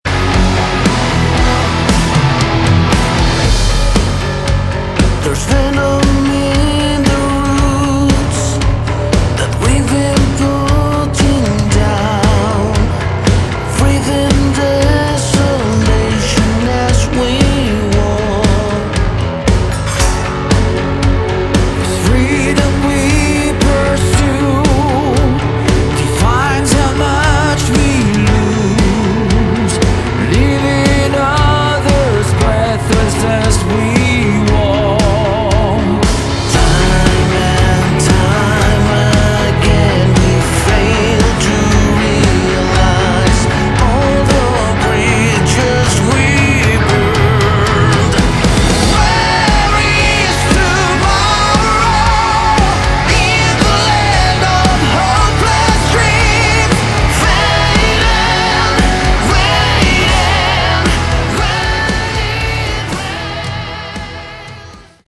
Category: Melodic Metal
vocals, guitars, bass, piano, programming
drums